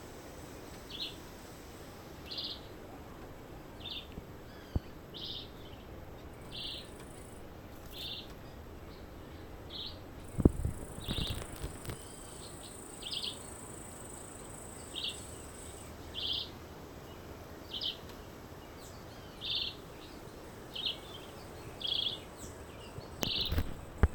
Chivi Vireo (Vireo chivi)
Class: Aves
Detailed location: Área Natural Yrigoyen (Yrigoyen y el Río)
Condition: Wild
Certainty: Observed, Recorded vocal